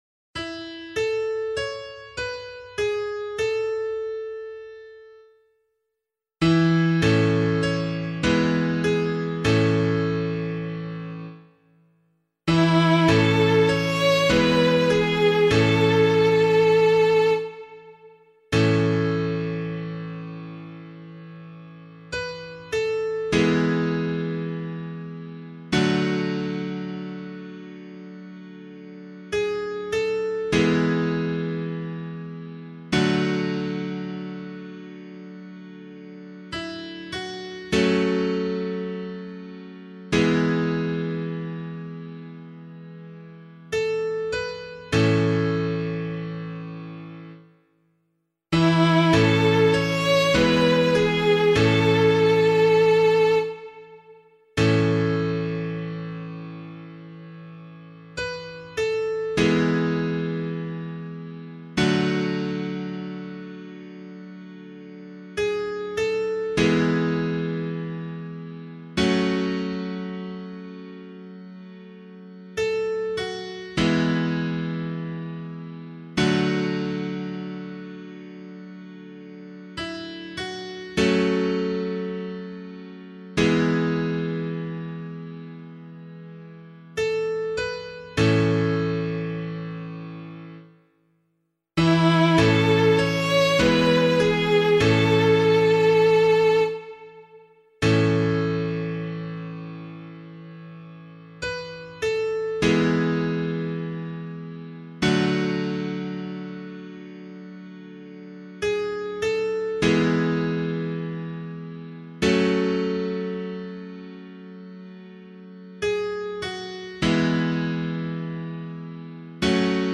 The Holy Family of Jesus, Mary and Joseph: Responsorial Psalm, Year C option
011 Baptism of the Lord Psalm C [LiturgyShare 8 - Oz] - piano.mp3